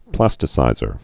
(plăstĭ-sīzər)